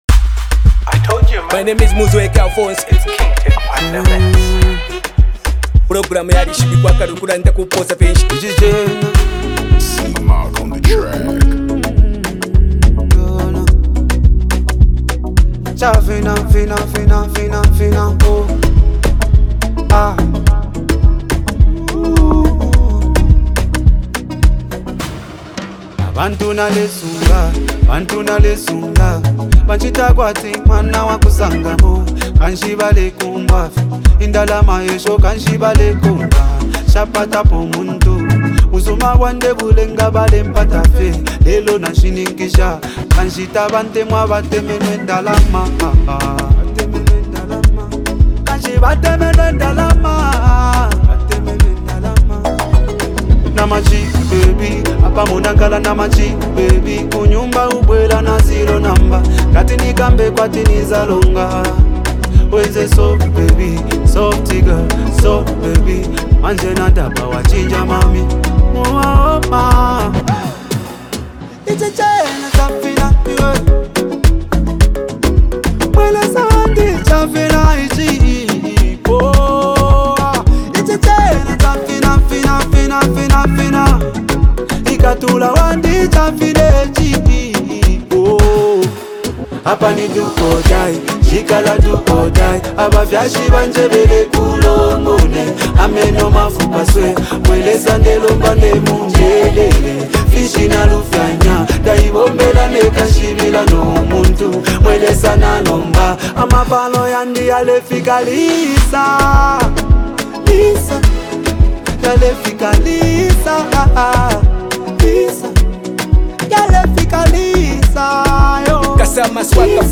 delivering soulful vocals that express deep affection